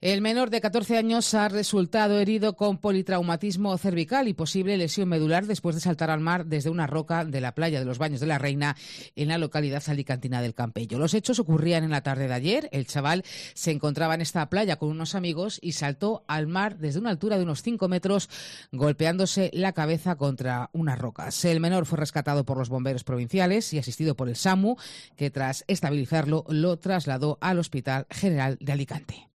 Herido un niño tras saltar al mar desde una roca en El Campello (Alicante). Crónica